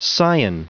Prononciation du mot scion en anglais (fichier audio)